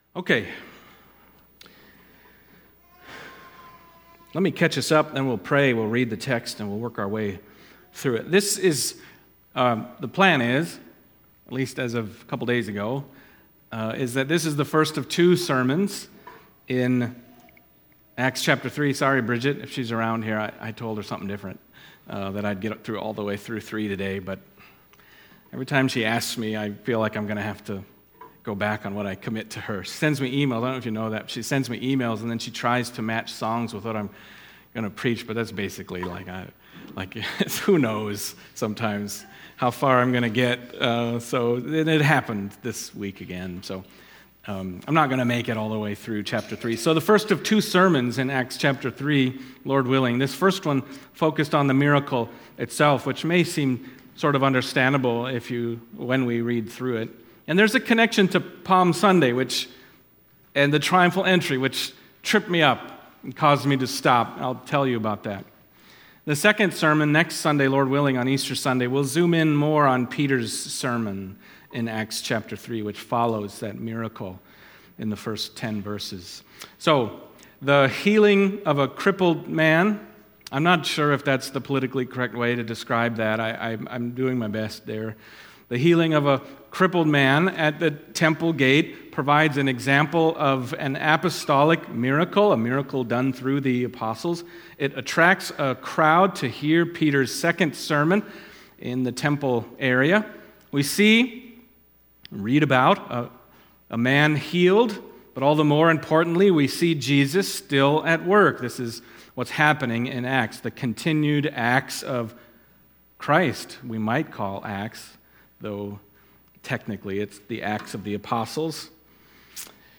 Acts Passage: Acts 3:1-26 Service Type: Sunday Morning Acts 3:1-26